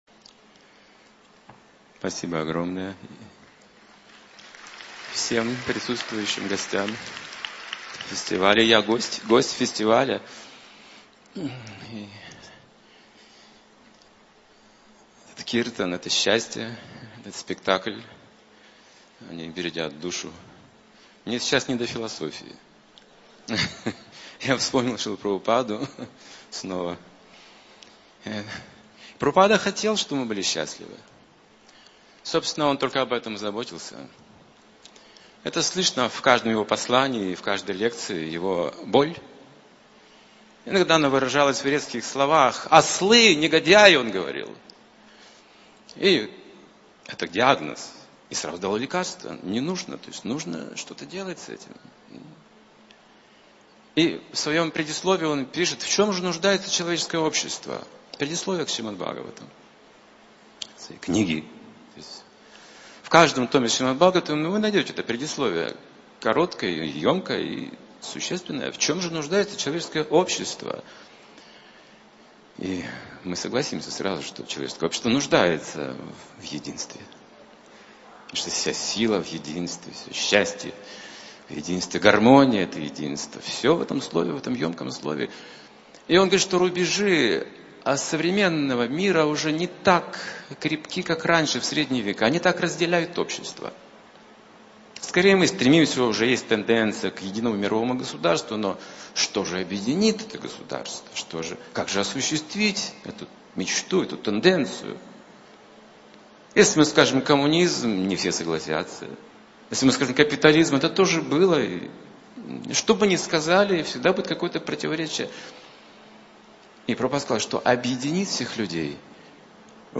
Голока фест (2015, Москва)